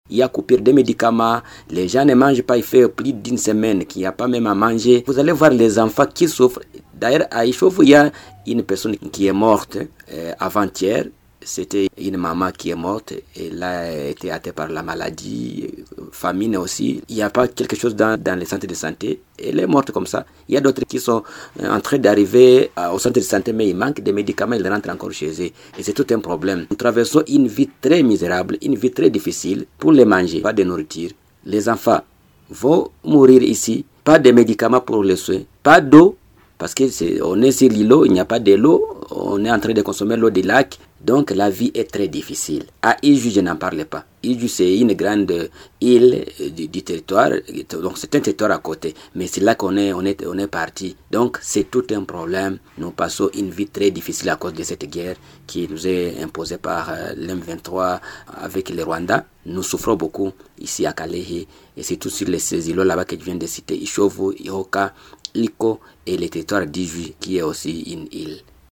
La société civile de la chefferie de Buhavu a lancé jeudi 13 février un appel à l’aide en direction des humanitaires et des autorités provinciales et nationale.